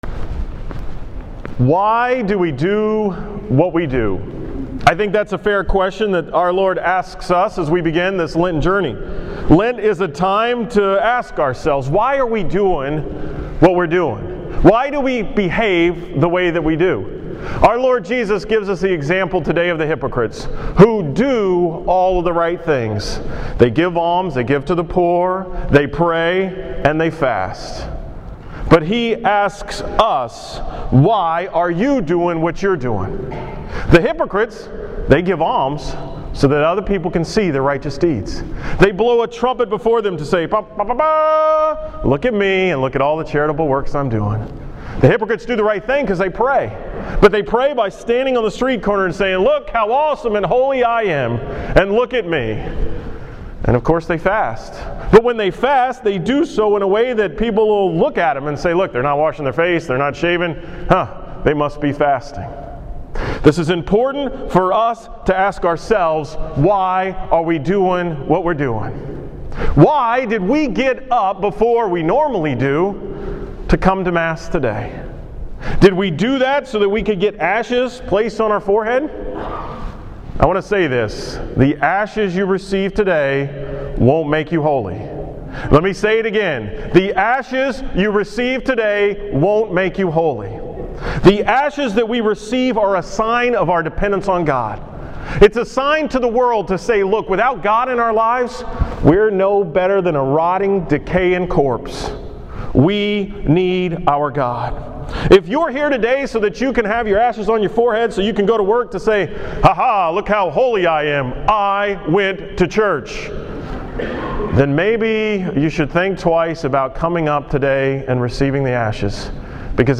From the Ash Wednesday 6:30 am Mass.
Category: 2014 Homilies